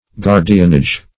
Guardianage \Guard"i*an*age\, n.